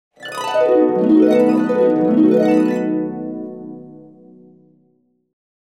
SFX – HARP (DREAM SEQUENCE)
SFX-HARP-(DREAM-SEQUENCE).mp3